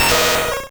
Cri de Grotadmorv dans Pokémon Rouge et Bleu.